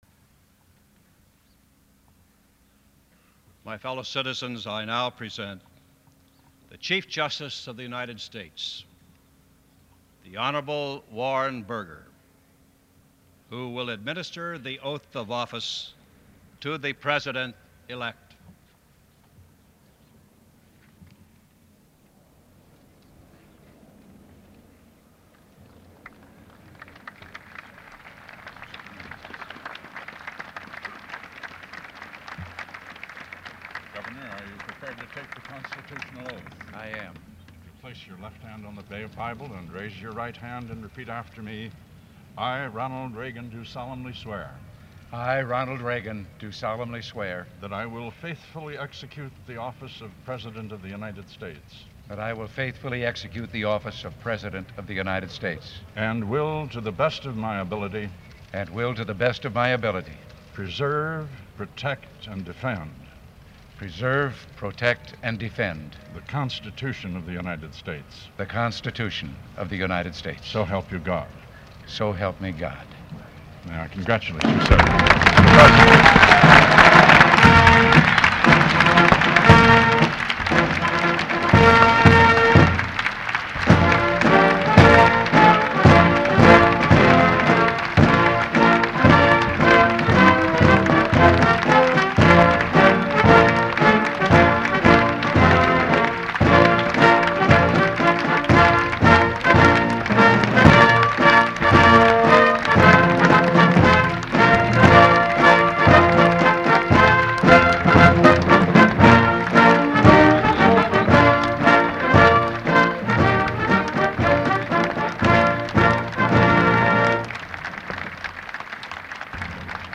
January 20, 1981: First Inaugural Address